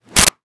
Sword Whip 04
Sword Whip 04.wav